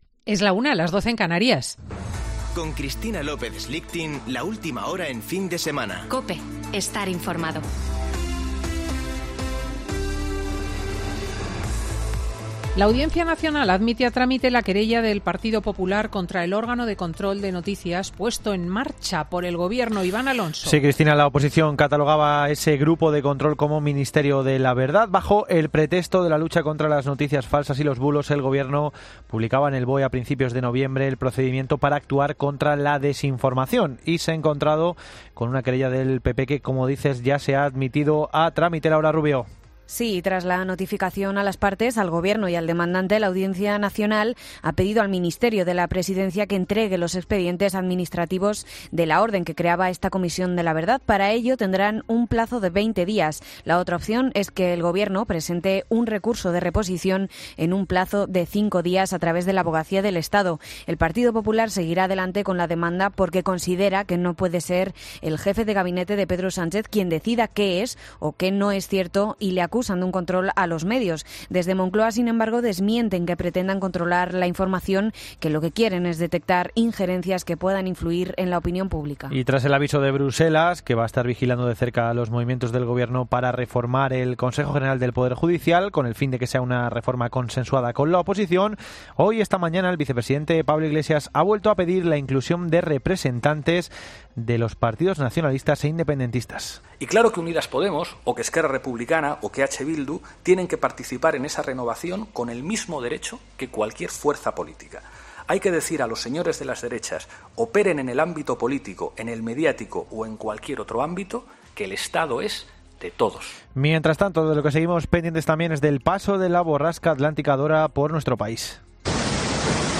Boletín de noticias de COPE del 5 de diciembre de 2020 a las 13.00 horas